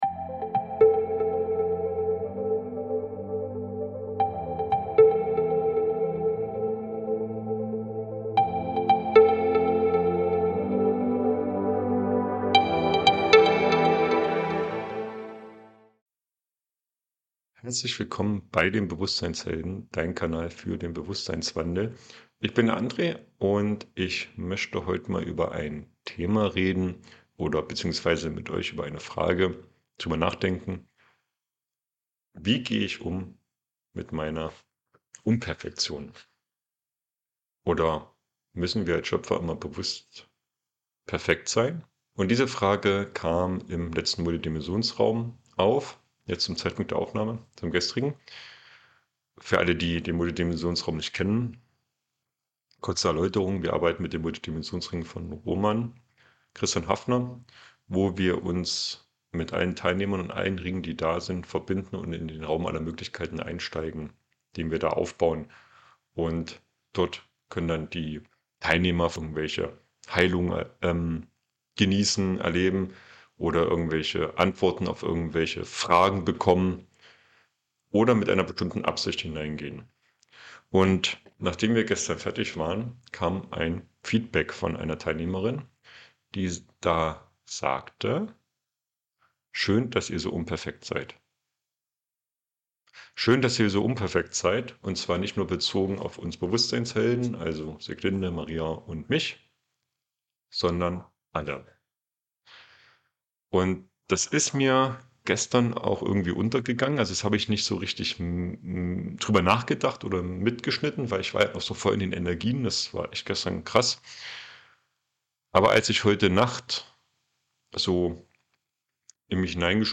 Heute gibt es eine neue Solo-Podcast-Episode, die uns alle